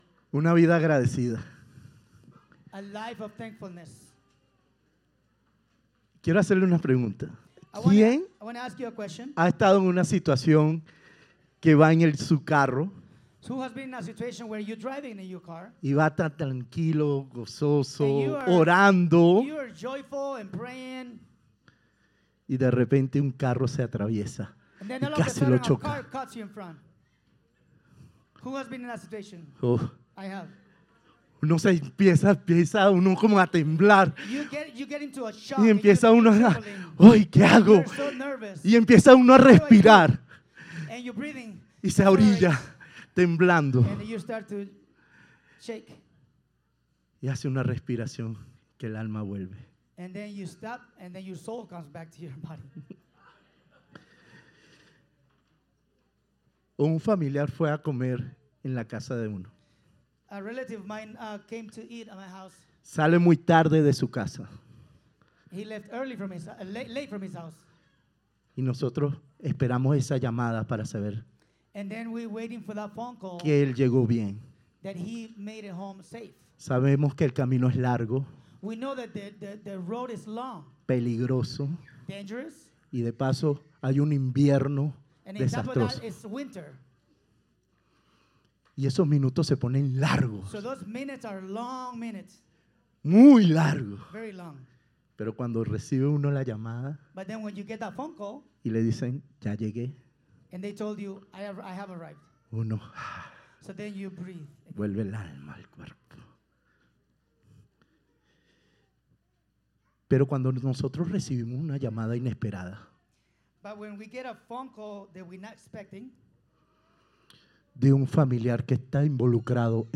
Sermons – Providence Bible Church